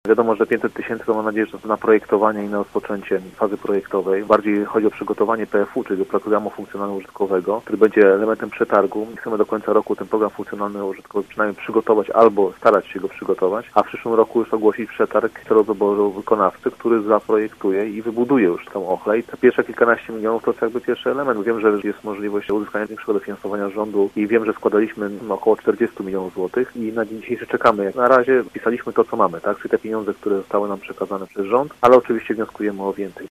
W uchwale budżetowej znalazł się zapis dotyczący zwiększenia budżetu miasta na ten cel w związku z otrzymaniem środków z rządowego Funduszu Inwestycji Lokalnych w wysokości 15 mln. 883 tysięcy złotych mówił dziś w programie Miejski Punkt Widzenia Piotr Barczak – przewodniczący Rady Miasta Zielona Góra, z klubu radnych Prawo i Sprawiedliwość,